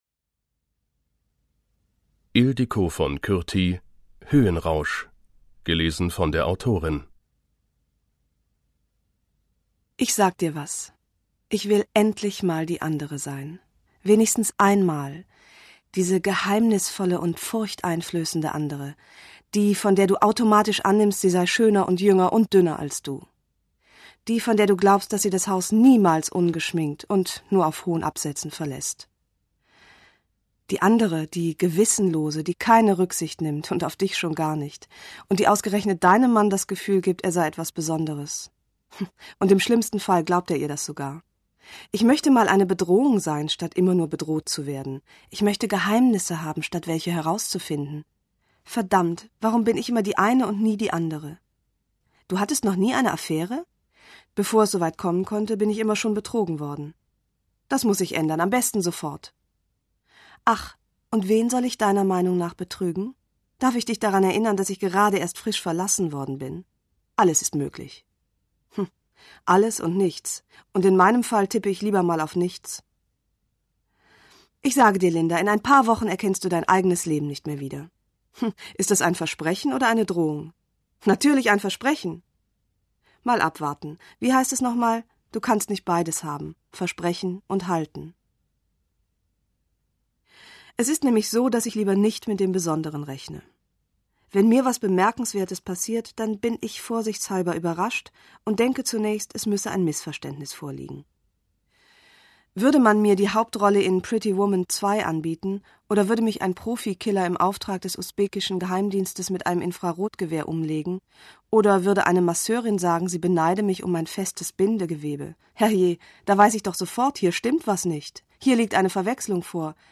Höhenrausch Ildikó von Kürthy (Autor) Ildikó von Kürthy (Sprecher) Audio-CD 2009 | 4.